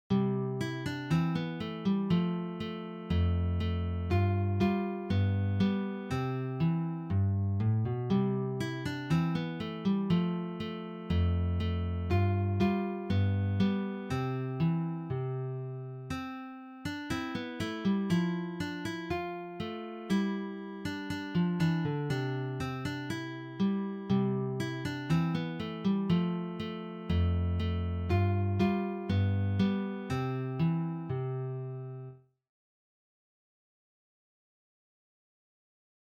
Für Gitarre Solo
Geistliche Musik
Gitarre (1)